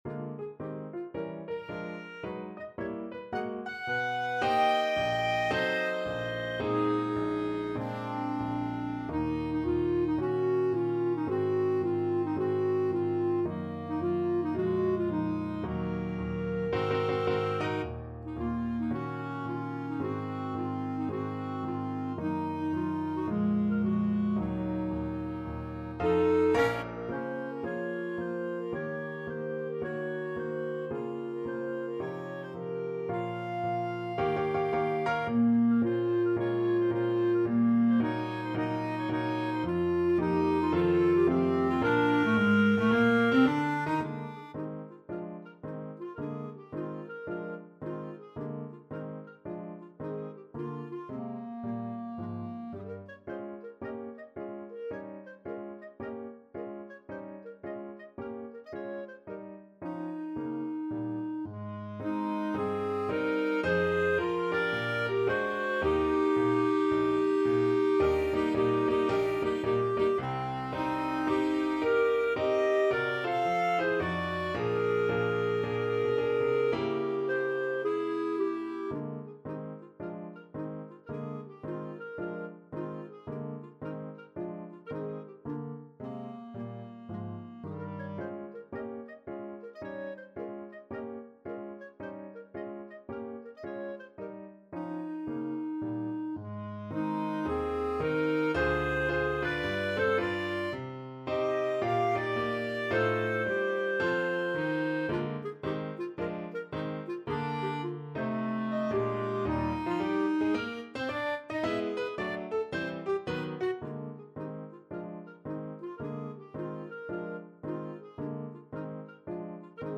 Clarinet 1Clarinet 2
Moderato =110 swung
4/4 (View more 4/4 Music)
Classical (View more Classical Clarinet Duet Music)